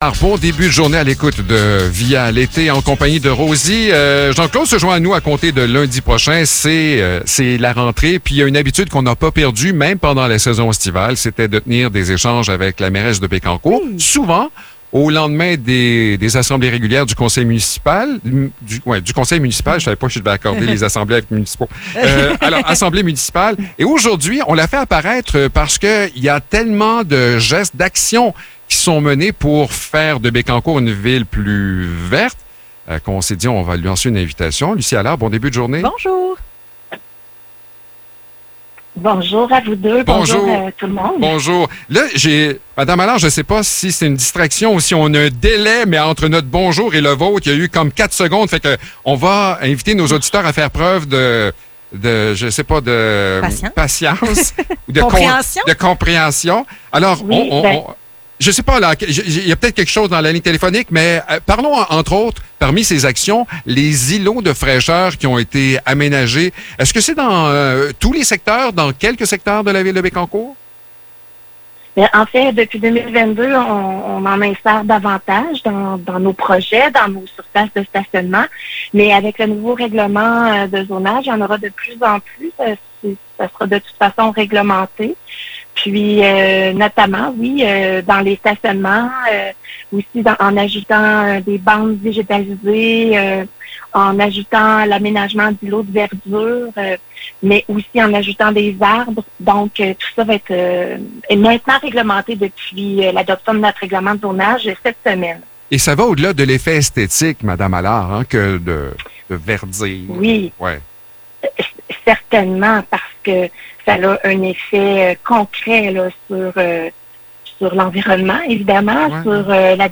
Échange avec la mairesse Lucie Allard